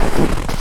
High Quality Footsteps / Snow
STEPS Snow, Walk 15.wav